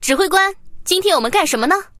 Yak1_loginvoice.mp3